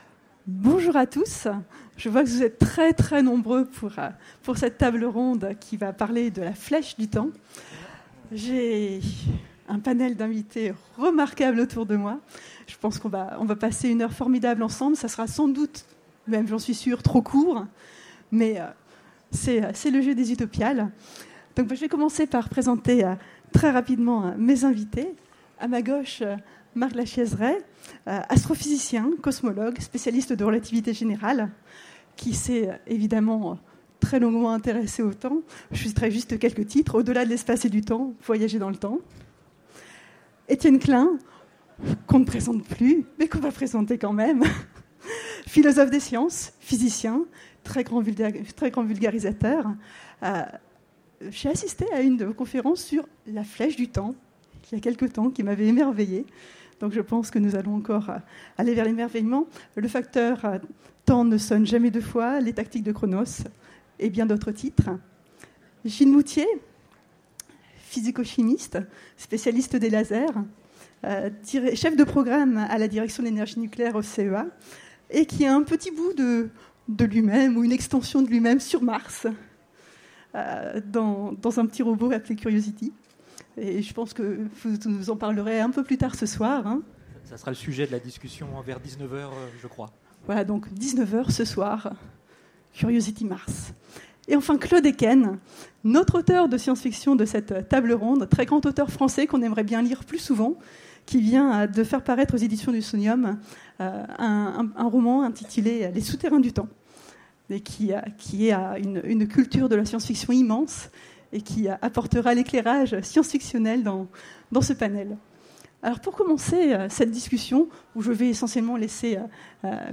Utopiales 2017 : Conférence La flèche du temps